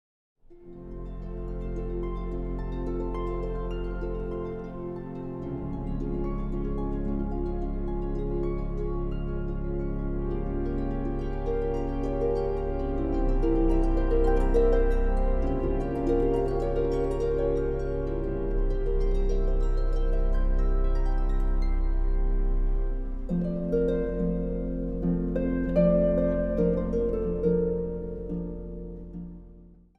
Instrumentaal | Harp